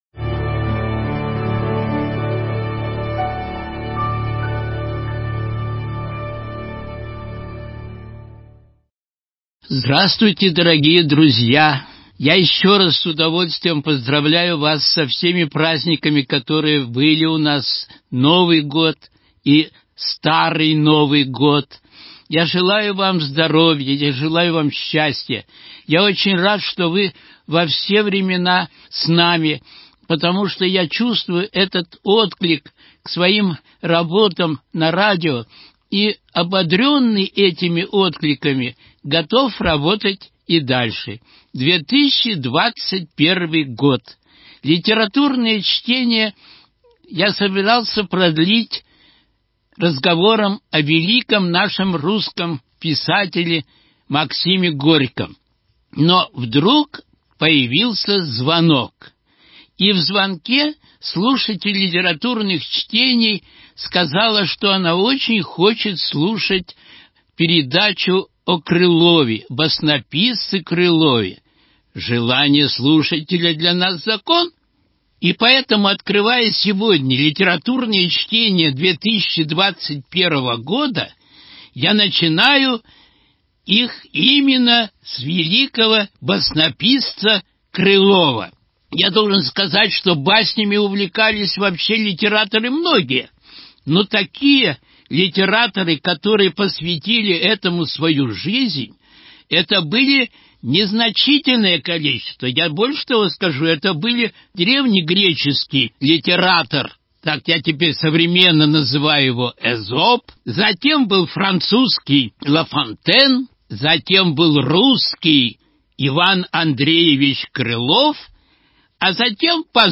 Литературные чтения: Басни Ивана Андреевича Крылова 29.01.2021